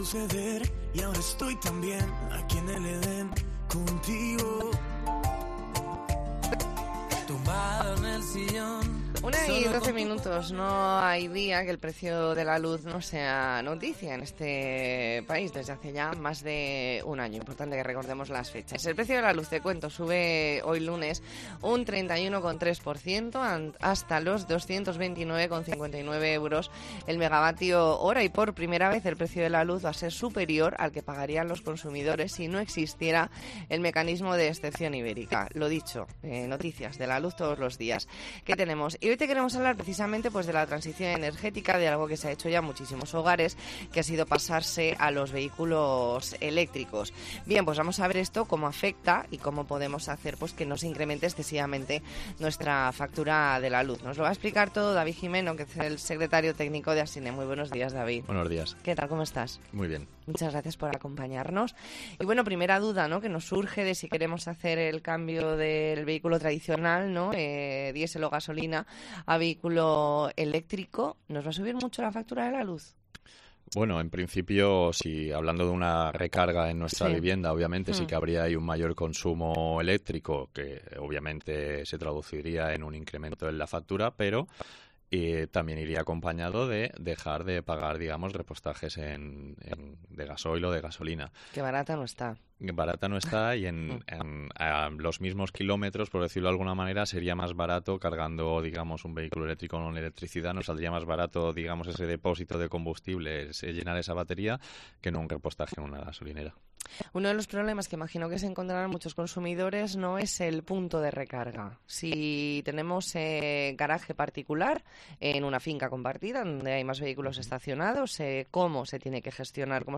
ntrevista en La Mañana en COPE Más Mallorca, lunes 10 de octubre de 2022.